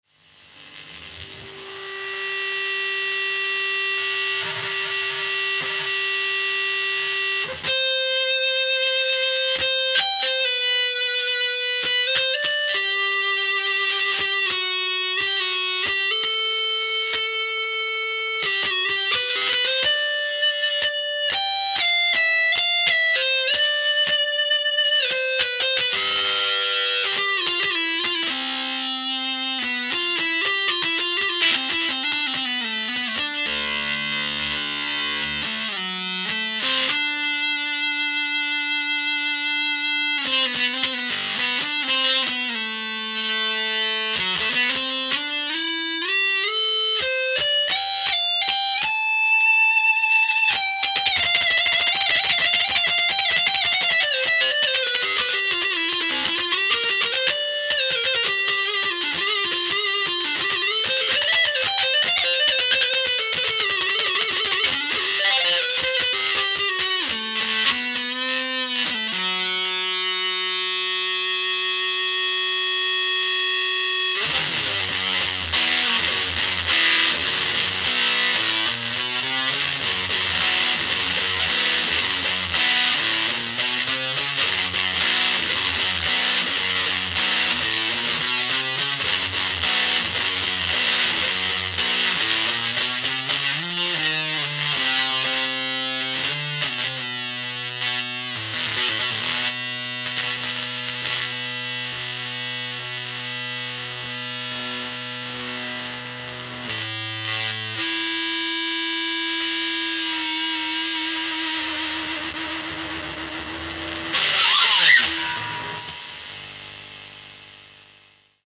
Page 3 - L'équipe de TG a fait des samples d'une fuzz face passé au congelo 20-30min.